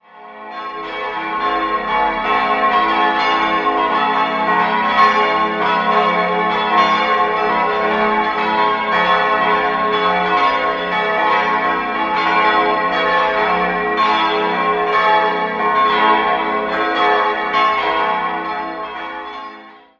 Die Glocken 2 und 4 goss im Jahr 1960 Friedrich Wilhelm Schilling, die große Glocke von Hans Glockengießer wurde 1517 in Nürnberg hergestellt. Bei der mittleren Glocke handelt es sich um ein Werk von Christof Glockengießer aus der zweiten Hälfte des 16. Jahrhunderts und die kleine Glocke entstand 1997 in der Karlsruher Glocken- und Kunstgießerei.